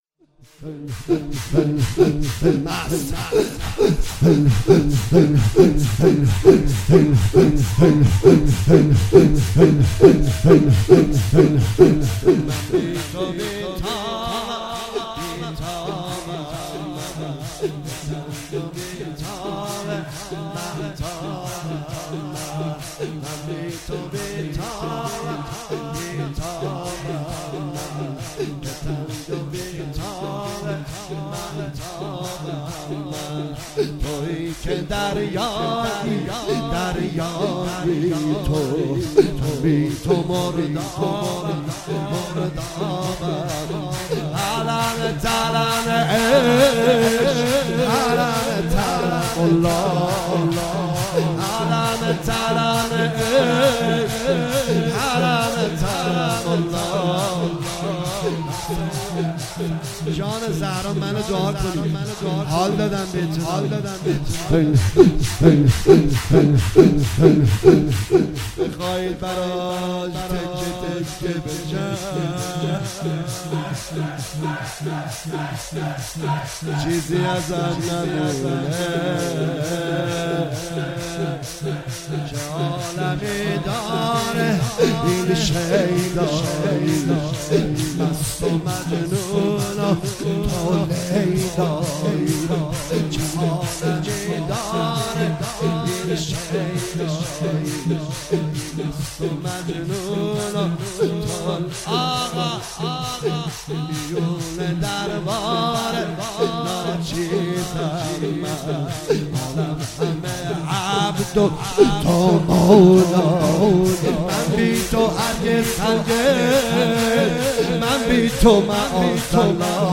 4 آبان 97 - هیئت مکتب الحسین - شور پایانی